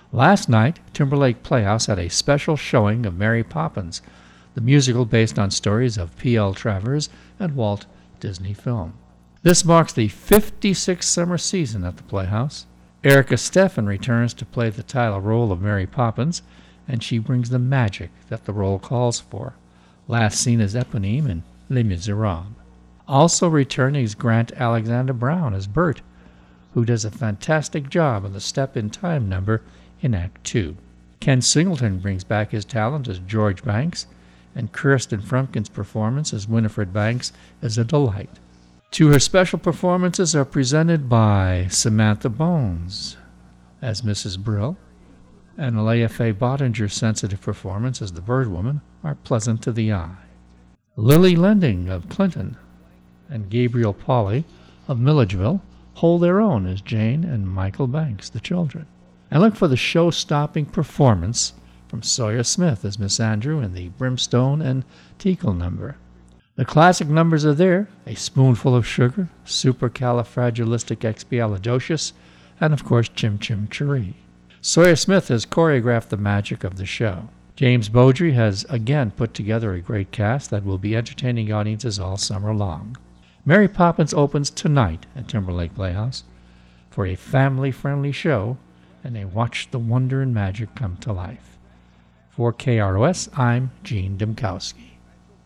Mary-Poppins-Review.wav